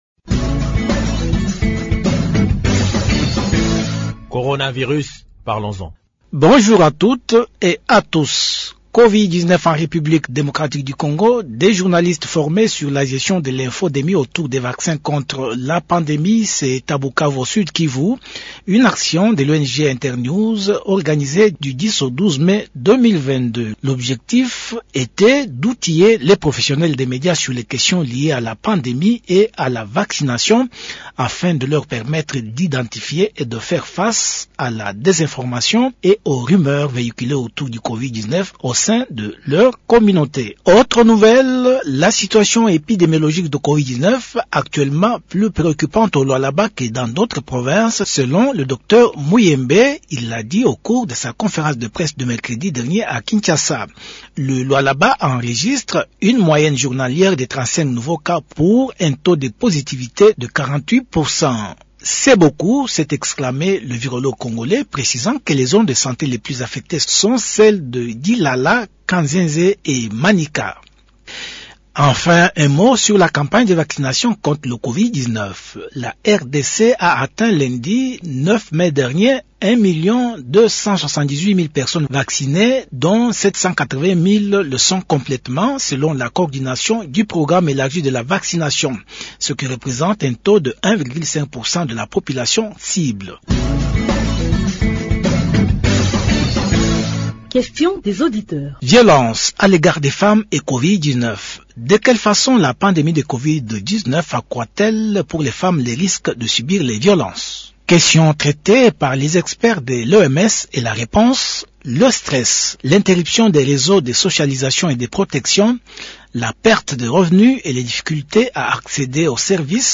Question